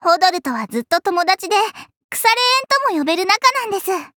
文件 文件历史 文件用途 全域文件用途 Ja_Fifi_amb_05.ogg （Ogg Vorbis声音文件，长度4.2秒，105 kbps，文件大小：53 KB） 源地址:游戏语音 文件历史 点击某个日期/时间查看对应时刻的文件。 日期/时间 缩略图 大小 用户 备注 当前 2018年5月25日 (五) 02:12 4.2秒 （53 KB） 地下城与勇士  （ 留言 | 贡献 ） 分类:祈求者比比 分类:地下城与勇士 源地址:游戏语音 您不可以覆盖此文件。